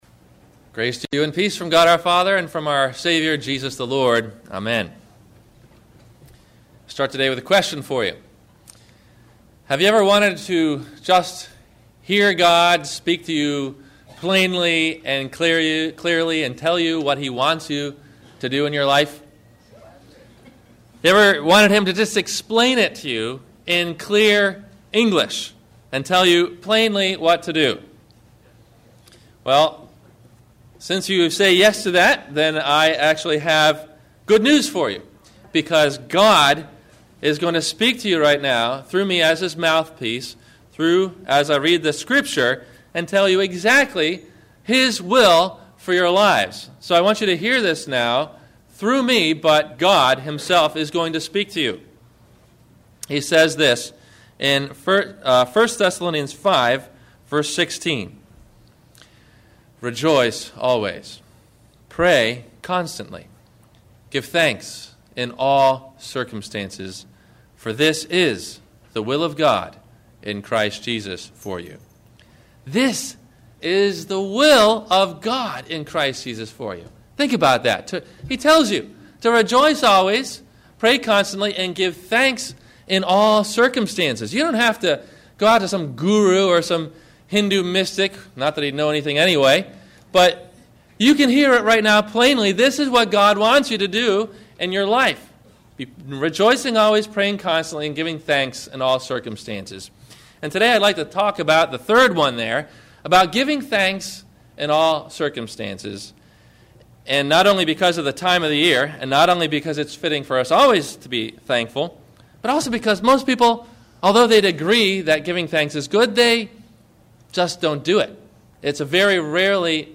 Thanksgiving – Sermon – November 23 2008